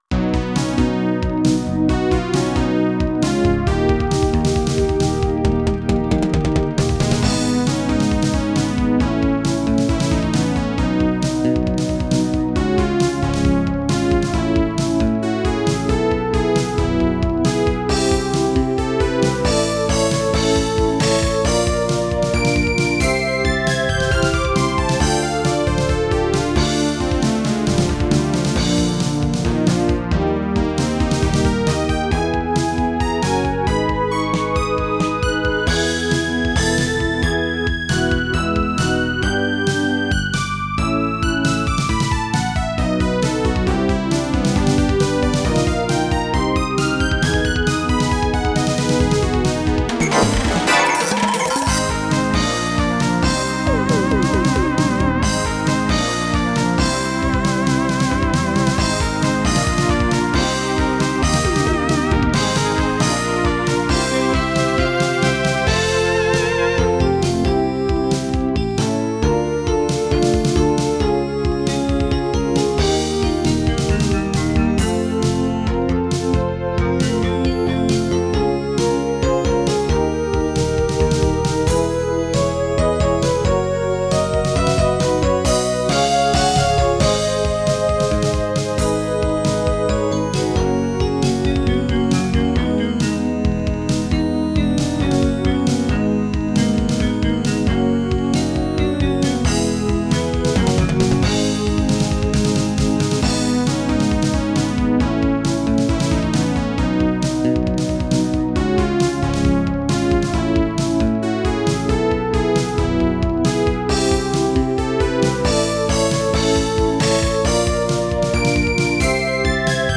EXPERIMENTAL MUSIC ; SYNTHESIZER MUSIC